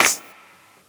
CLAPSNR.wav